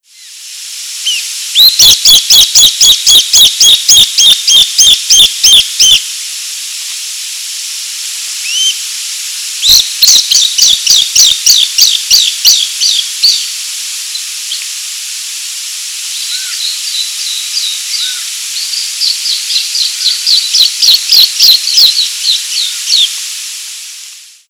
Streptoprocne zonaris zonaris - Vencejo de collar
vencejodecollar.wav